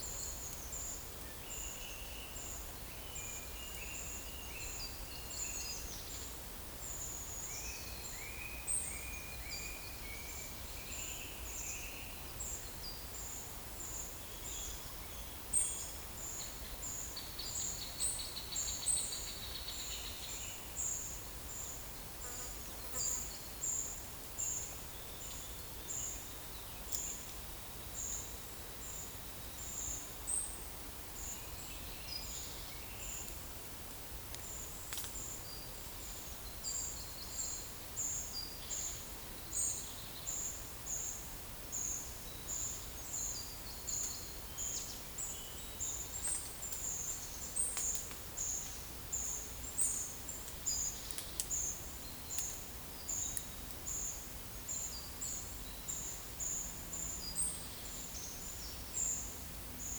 Monitor PAM
Certhia familiaris
Certhia brachydactyla
Aegithalos caudatus
Turdus iliacus
Sitta europaea